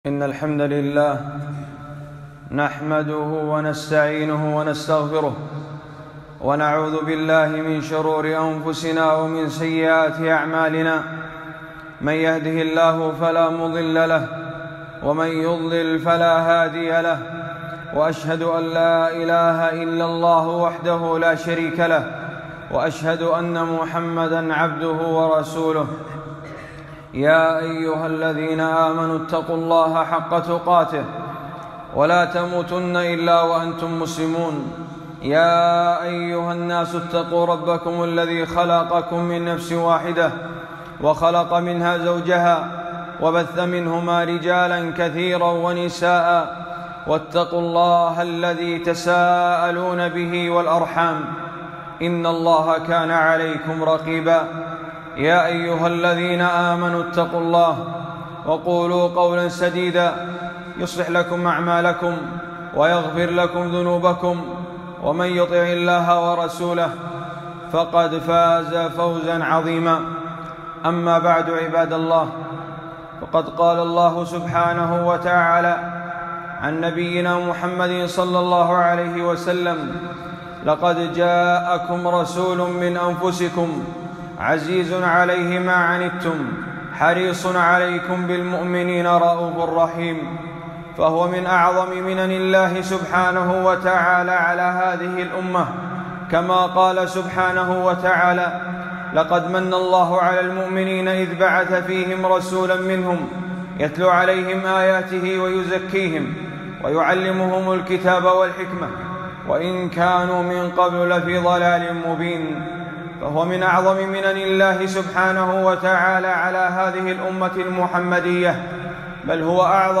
خطبة - من وصايا الرسول ﷺ قبل موته